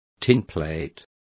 Complete with pronunciation of the translation of tinplate.